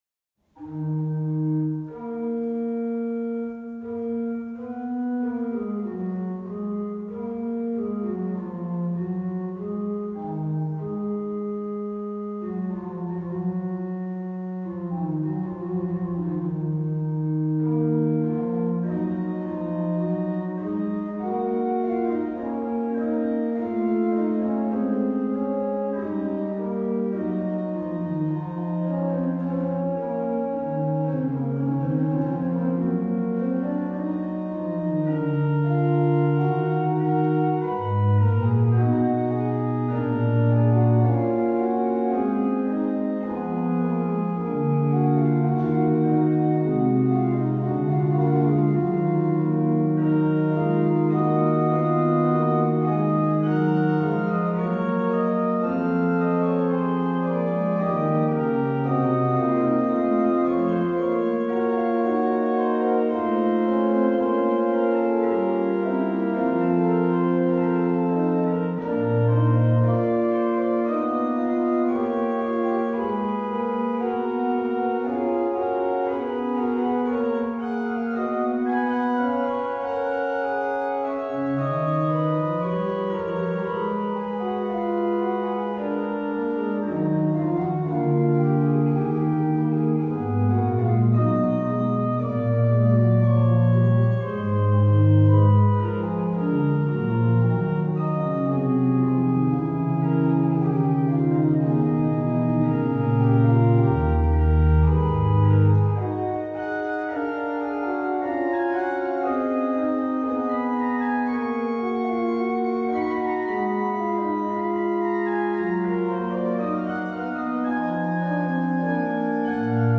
Registration   Pr8, Rfl4